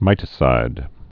(mītĭ-sīd)